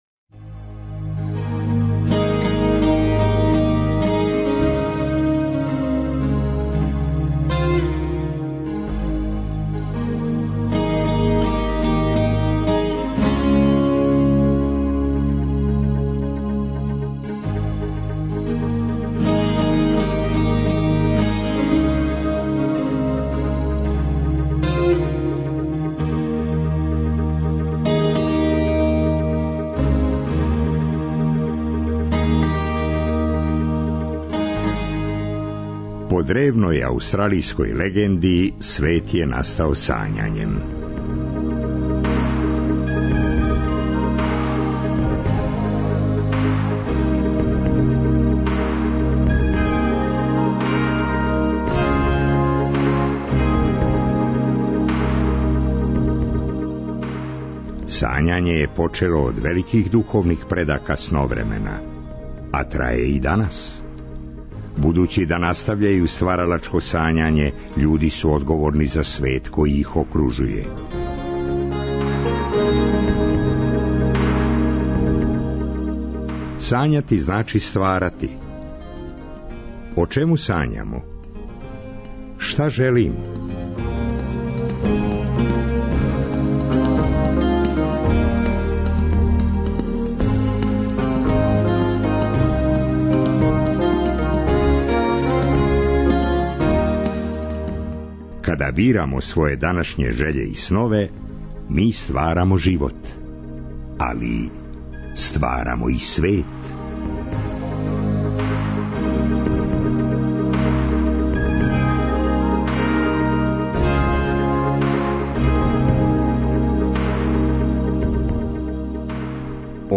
У трећем и четвртом сату емисије, уз песме домаћих бендова крећемо у пролећно лутање Београдом.